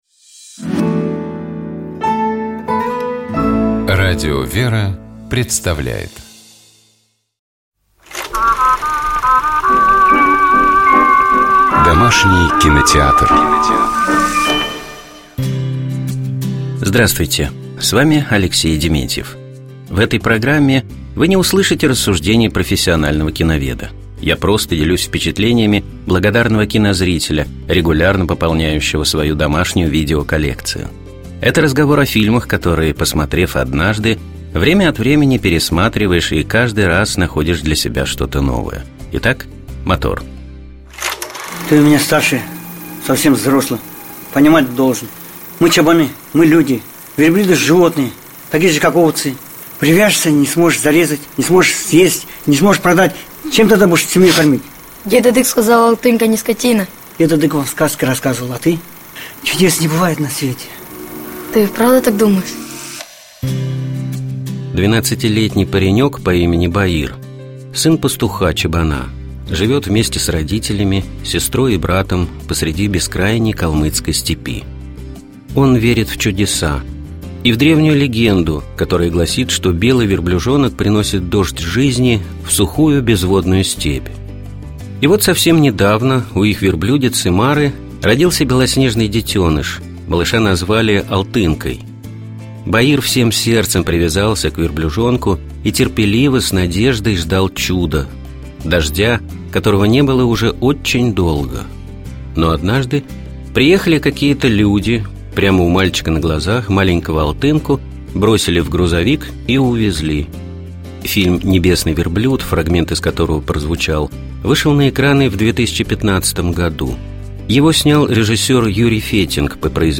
Фильм «Небесный верблюд», фрагмент из которого прозвучал, вышел на экраны в 2015 году.